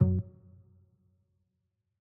bass.ogg